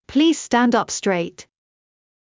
ﾌﾟﾘｰｽﾞ ｽﾀﾝﾀﾞ ｱｯﾌﾟ ｽﾄﾚｲﾄ